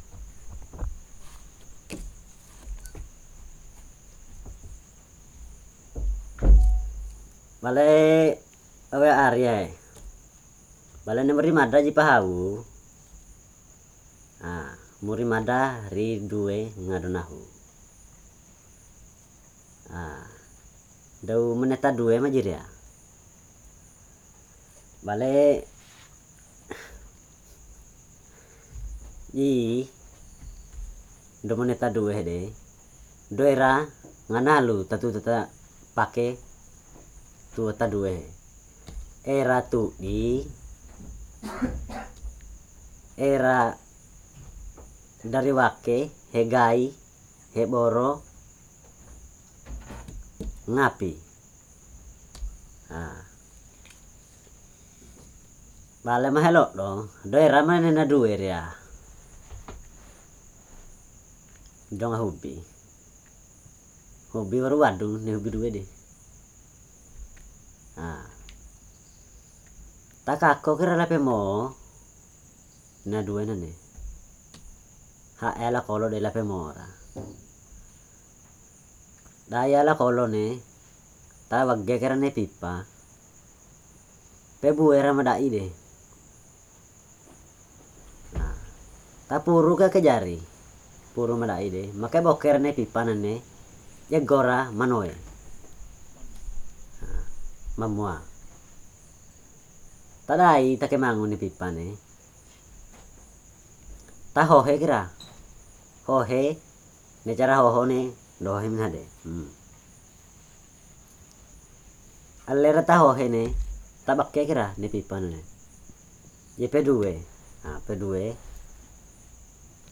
dc.description.regionDepe, Savu, Nusa Tenggara Timur, Indonesia; recording made in Depe
dc.formatdigital wav file recorded at 44.1 kHz/16 bit on Zoom H4N solid state recorder and Movo LV4-C cardiod